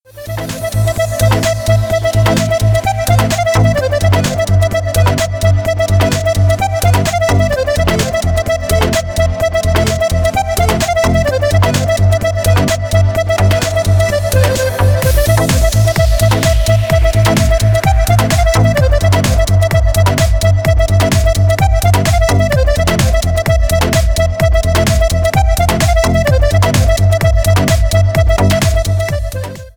Ремикс
клубные # без слов